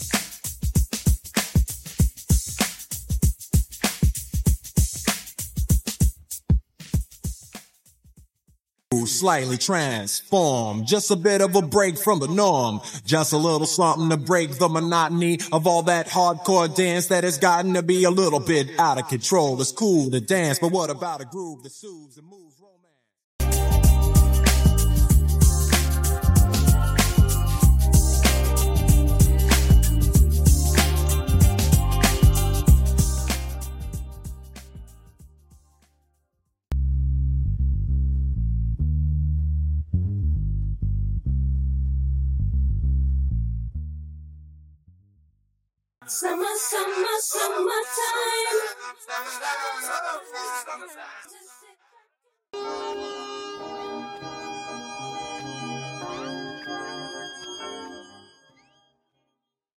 Leading Vocals Stem
Percussion & Drums Stem